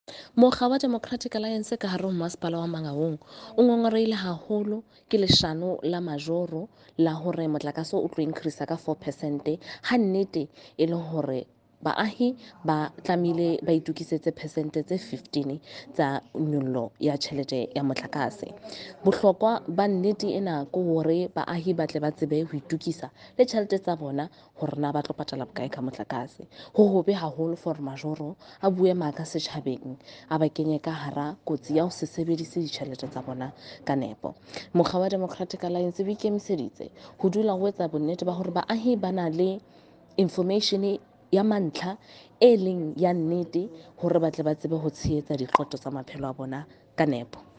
Sesotho by Karabo Khakhau MP.
Sotho-voice-Karabo-6.mp3